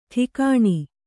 ♪ ṭhikāṇi